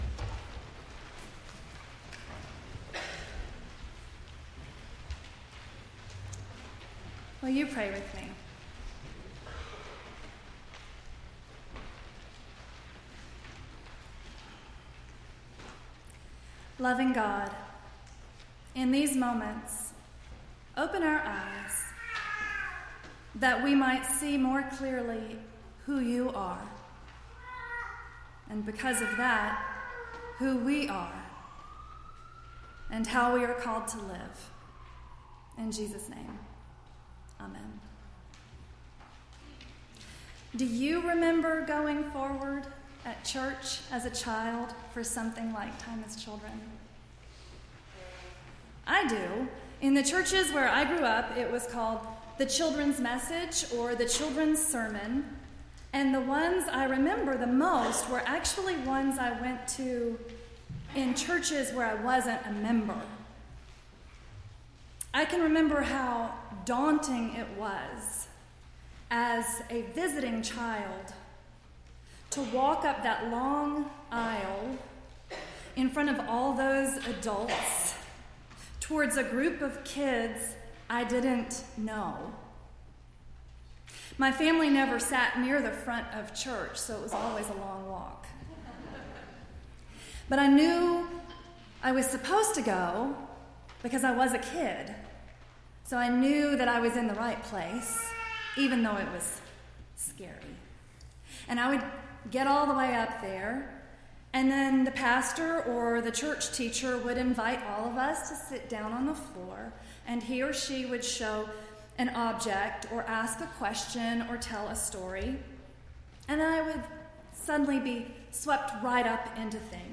3-26-17-sermon.mp3